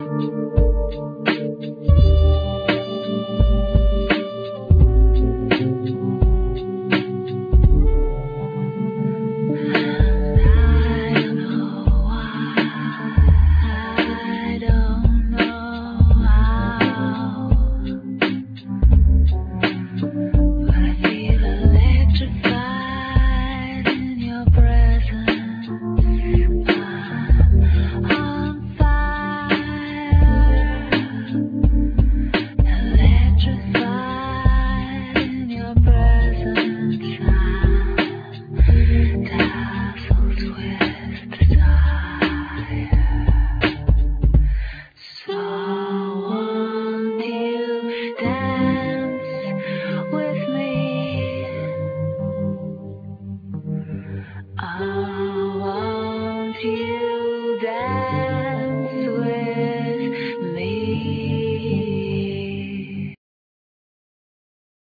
Vocals
Uillean pipes
Sax, Bass clarinet, Ba-wu flute
Acoustic guitar
Piano
Keyboards